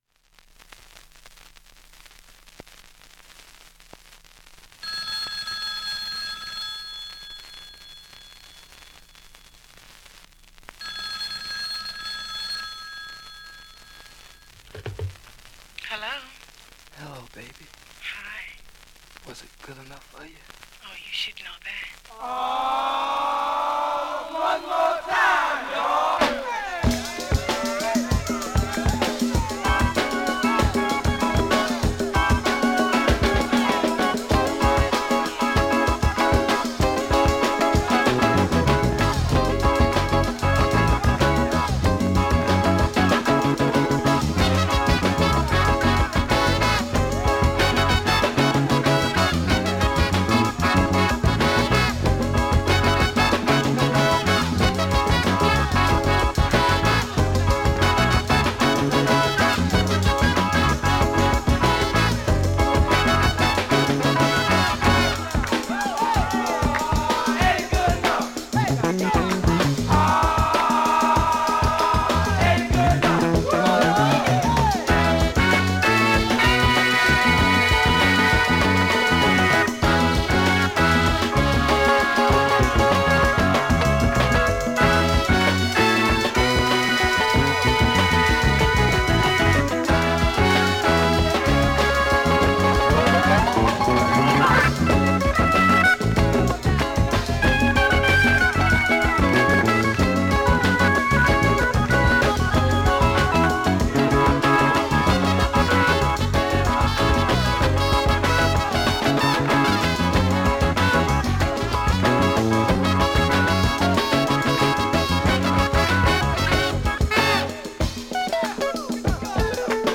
スレ多めですが普通に聴けます
現物の試聴（両面すべて録音時間６分２６秒）できます。